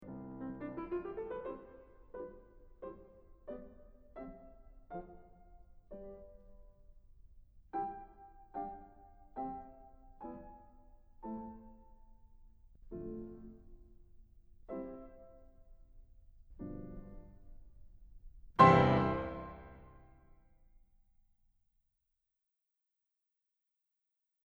Anyhow, Beethoven ends the sonata with the same “big bang surprise” effect: